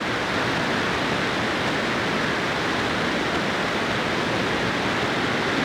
Dirty Electricity - May 2025 at 4 Mhz on a shortwave radio, contributing to chronic disease, undermining humanity.